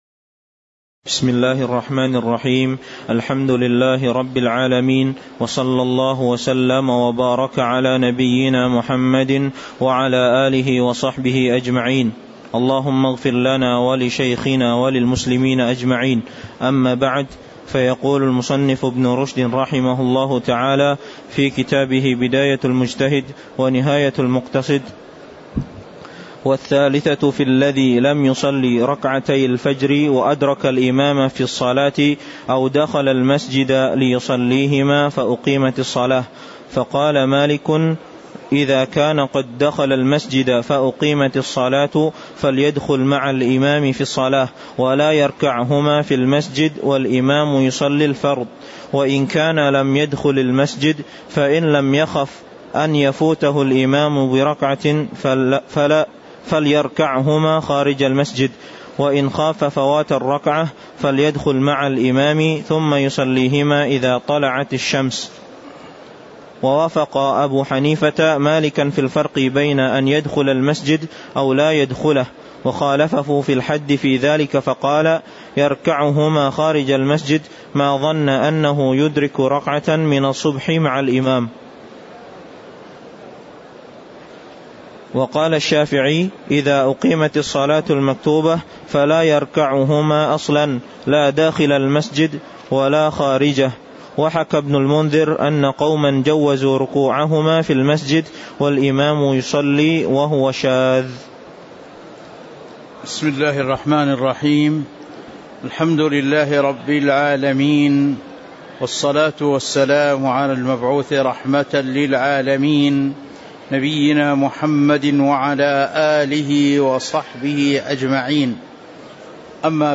تاريخ النشر ٨ جمادى الآخرة ١٤٤٤ هـ المكان: المسجد النبوي الشيخ